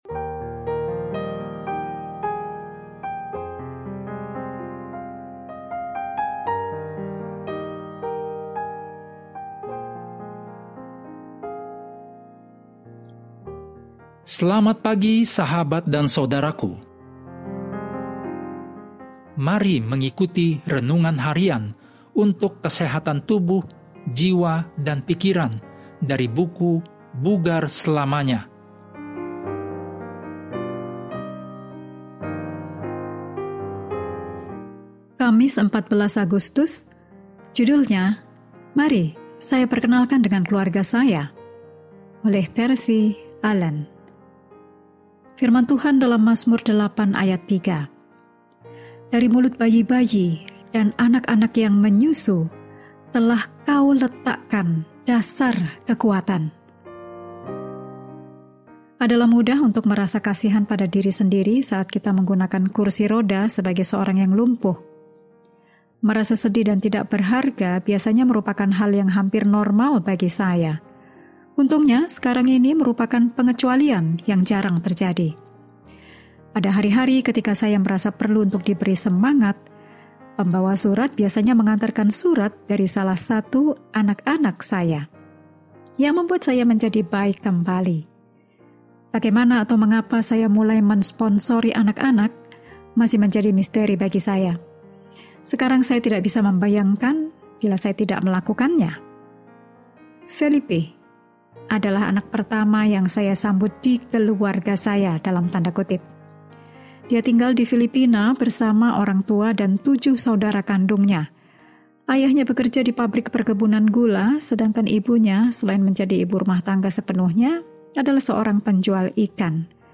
Daily Spiritual Devotional in Indonesian from Adventist World Radio